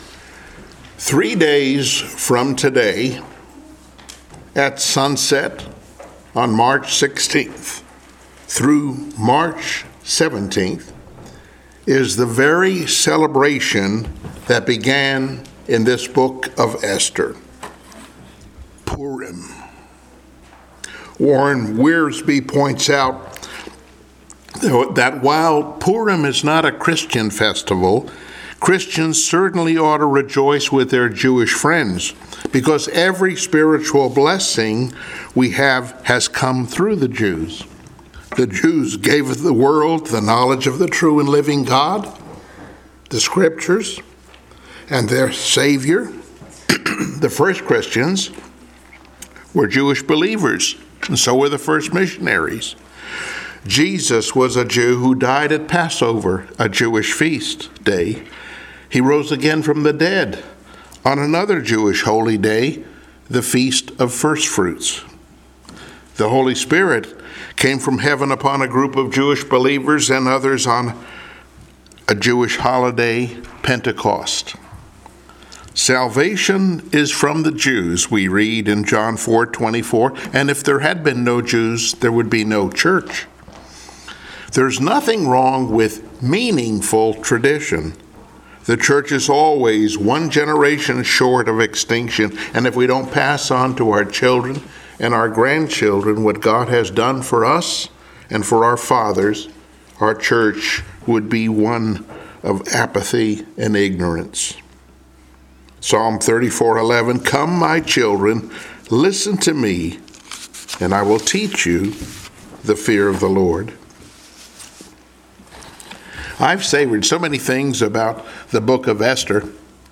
Passage: Esther 10 Service Type: Sunday Morning Worship